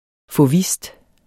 Udtale [ foˈvisd ]